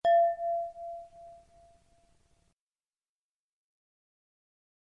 audio-bell-04.mp3